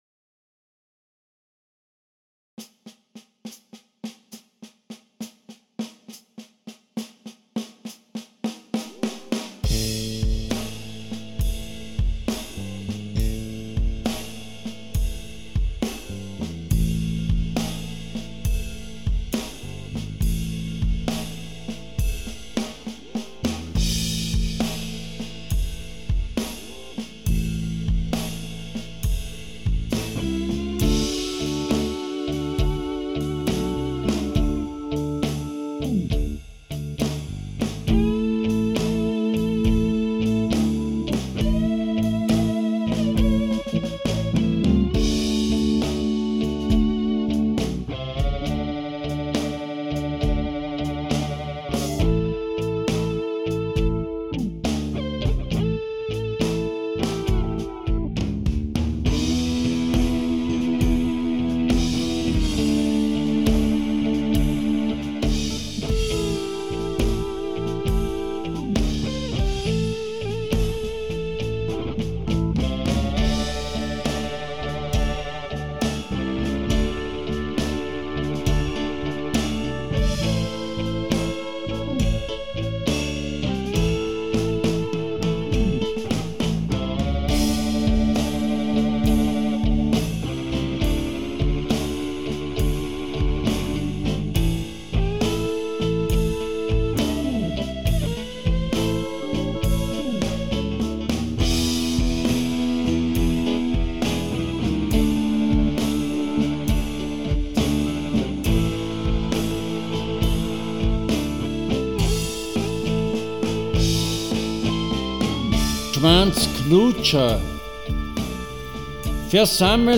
The music is secondary, only the frame.
And this is the second part of the double-live-CD.
Drums, Percussion, Bass, Guitar, Synthesizer
completed with my drums and some keyboard lines,